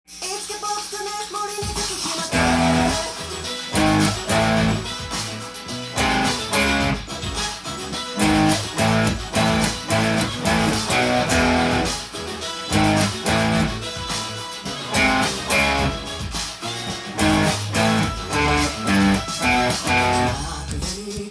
音源にあわせて譜面通り弾いてみました
はイントロからおしゃれなリズムで始まる曲です。
弾きやすいように全てパワーコードで弾けるようにtab譜に
とにかく休符が多いイントロです。